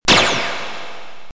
Photon gun shot
Tags: Photon Sounds Photon Sound Photon clips Sci-fi Sound effects